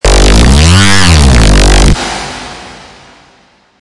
Download Riot sound effect for free.
Riot